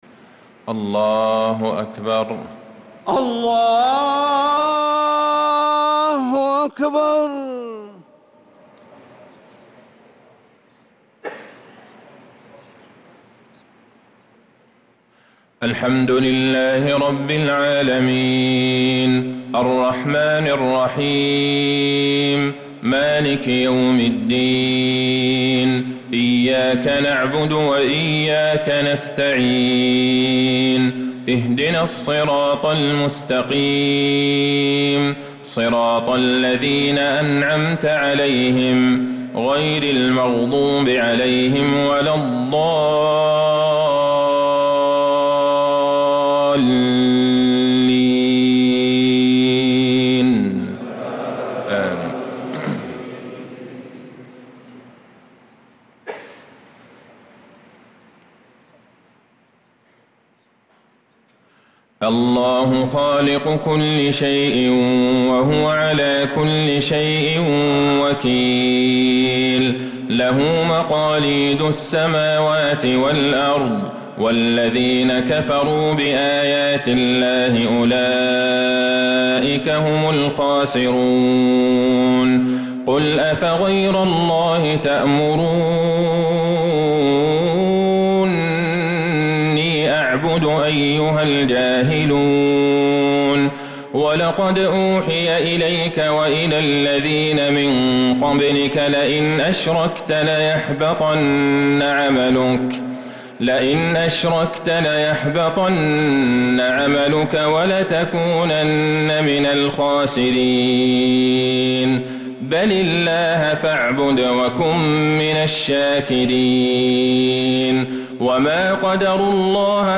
صلاة العشاء للقارئ عبدالله البعيجان 1 ربيع الآخر 1446 هـ